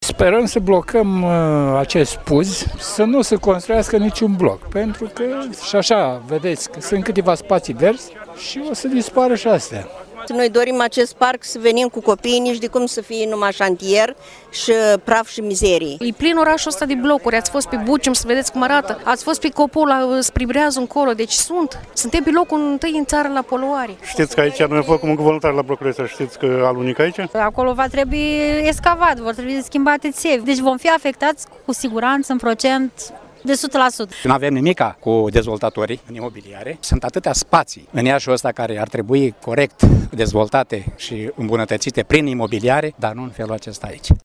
Aproximativ 200 de persoane au protestat, din nou, astăzi, pe Esplanada Oancea, din cartierul ieșean Tătărași, împotriva a două proiecte imobiliare, aprobate deja, care presupun construirea a trei blocuri cu 6, 8 și 10 etaje, pe locul în care, acum, se află spații verzi și un parc de joacă.
26-apr-ora-21-vox-Oancea.mp3